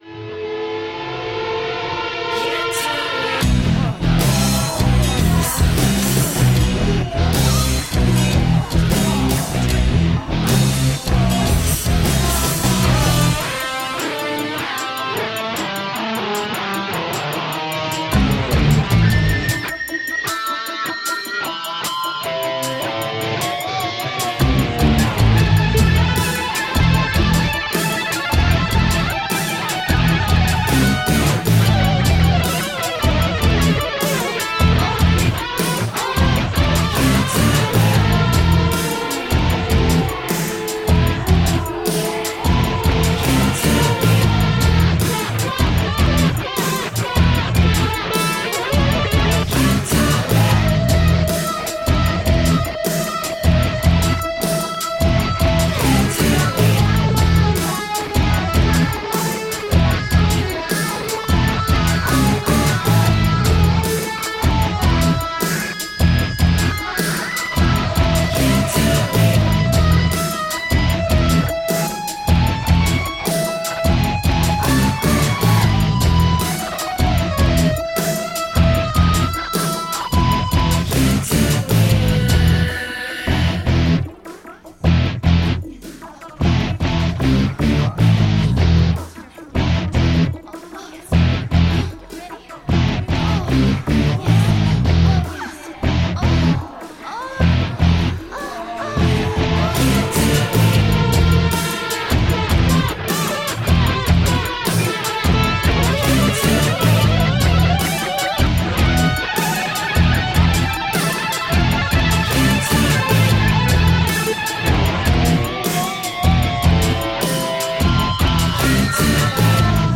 Guitar-edged industrial electrorock.